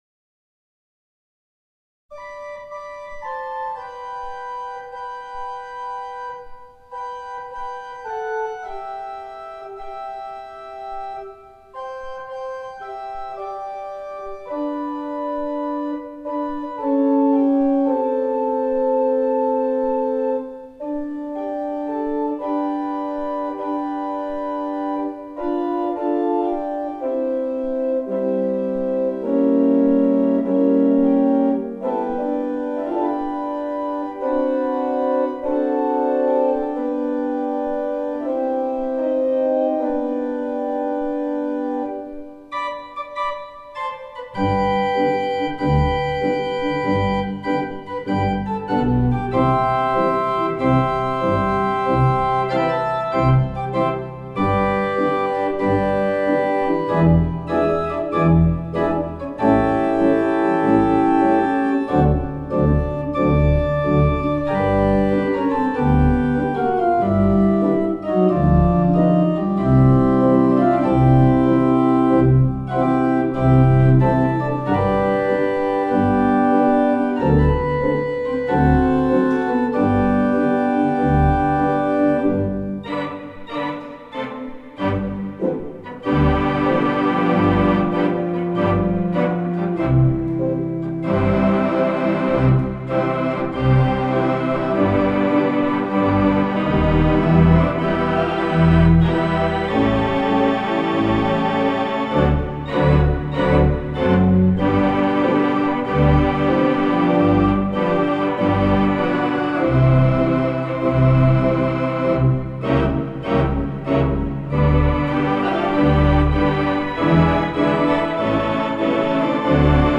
Free music downloads from the 4/89 Mighty Digital 460S Church Organ at the All Faith Chapel in Ridgecrest, California
They are more akin to the kind of music one would hear from the Mighty WurliTzer Theatre Pipe Organ but played with a mountain gospel style.
In a couple of places, you will hear that unmistakable rumble of the mighty 64-footer.
The music we hear in the list of songs above was recorded live using a Samson Zoom H4 digital audio recorder, seen in the picture above.
To make the recordings, the Samson Zoom H4 digital audio recorder was mounted on the tripod and placed near the center of the congregational area about twelve rows back from the Holy Dias.